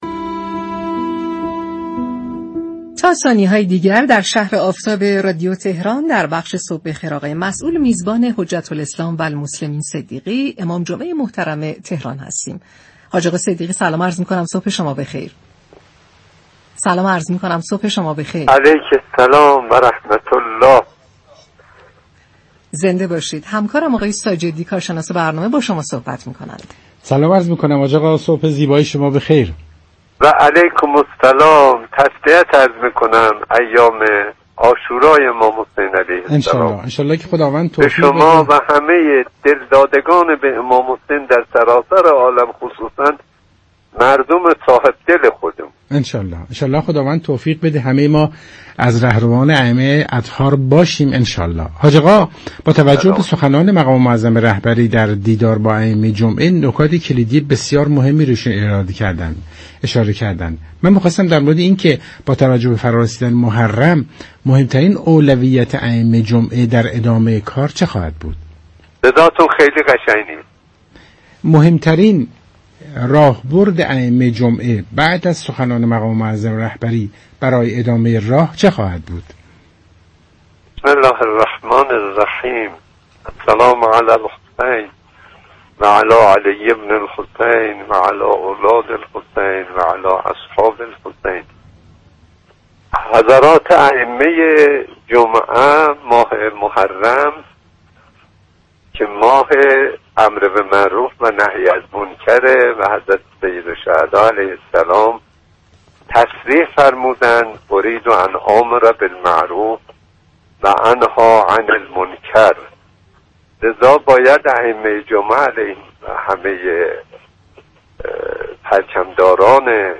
به گزارش پایگاه اطلاع رسانی رادیو تهران، حجت الاسلام والمسلمین كاظم صدیقی امام جمعه تهران در گفت‌و‌گو با شهر آفتاب رادیو تهران گفت: ماه محرم، ماه امر به معروف و نهی از منكر است لذا ائمه جمعه كه پرچم‌داران جبهه نماز جمعه در سراسر كشور هستند باید روح جدیدی در همه مردم بدمند و آنچه كه لازمه رشد فرهنگی، معنوی و اخلاقی جامعه است را با بیان متناسب با زمان به مردم ارائه كنند.